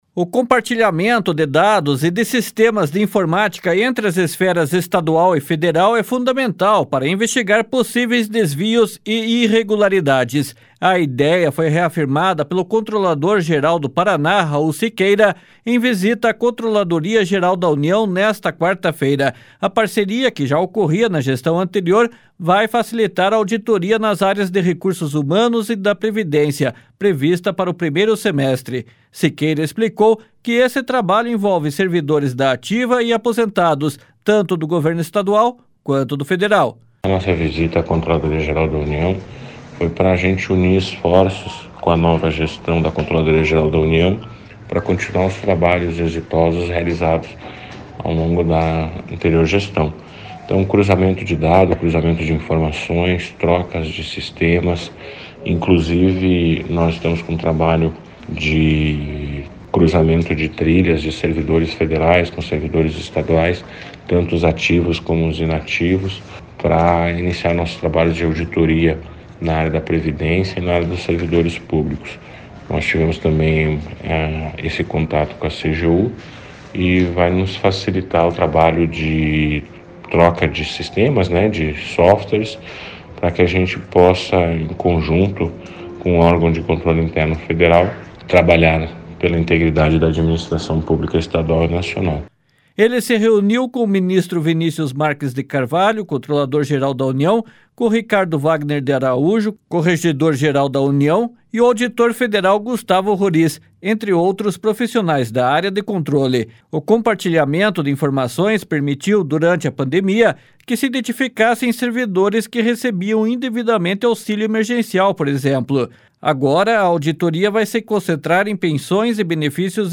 //SONORA RAUL SIQUEIRA//